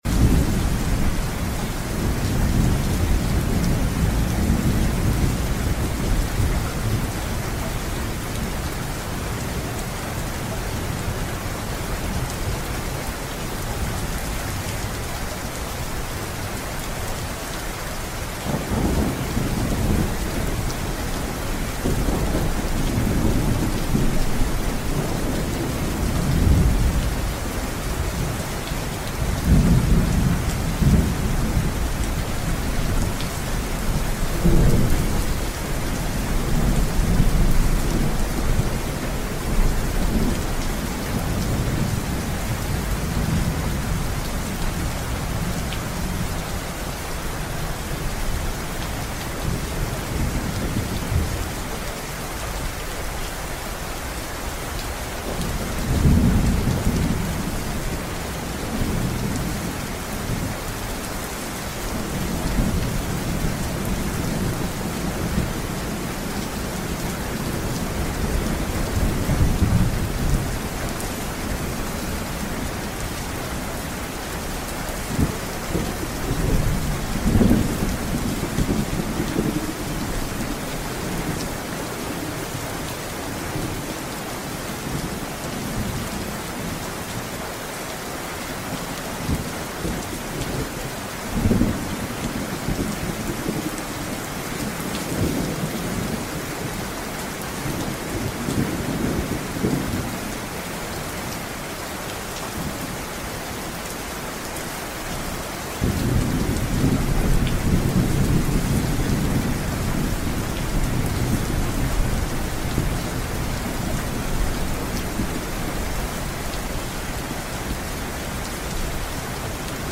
Drift into calmness as the rain falls gently over a quiet forest house. The soothing rhythm of raindrops blends with the stillness of nature, creating the perfect backdrop for your nightly sleep ritual. Let the peaceful soundscape ease your mind and guide you into deep rest.